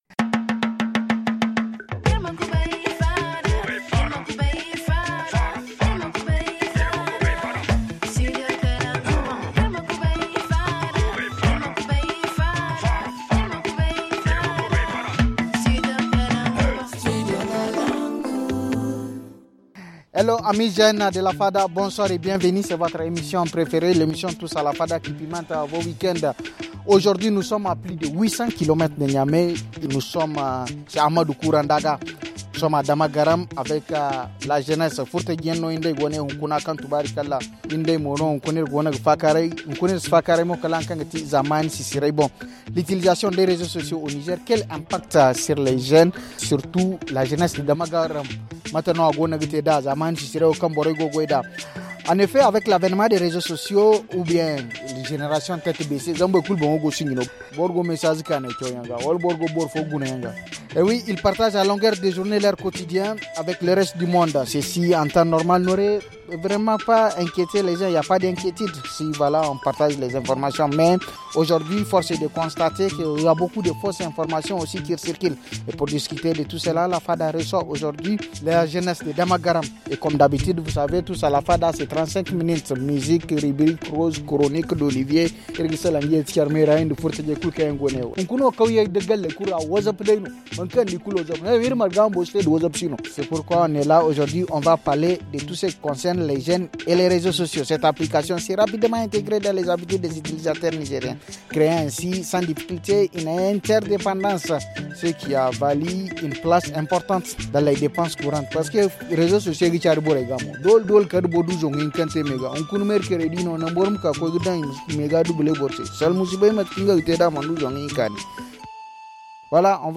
Ce soir, la fada est installée à plus 800 Kms de Niamey, sur la terrasse de la direction régionale de la jeunesse de Zinder.